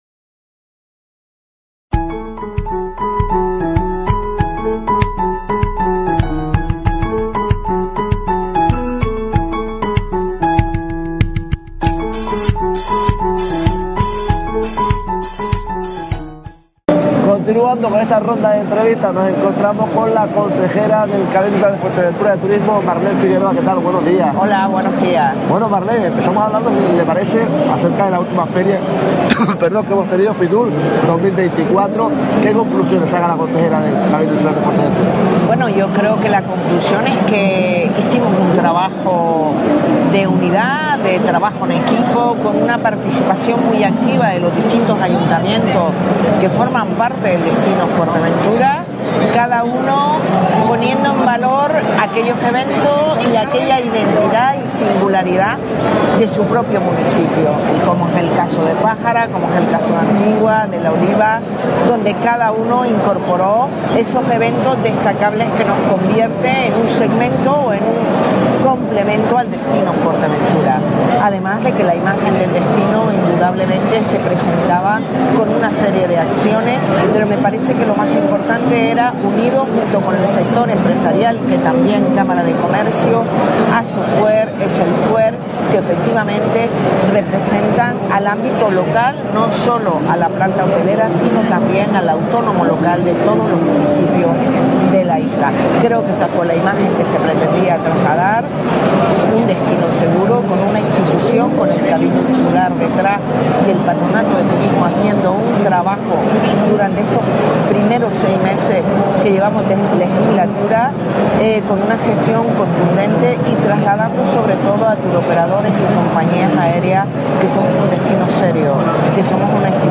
Madrid Fusión 2024: Entrevista a Marlene Figueroa 29/01/24
Entrevistamos a la consejera de Turismo del Cabildo Insular de Fuerteventura, Marlene Figueroa.